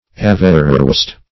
Meaning of averroist. averroist synonyms, pronunciation, spelling and more from Free Dictionary.
Averroist \A*ver"ro*ist\, n.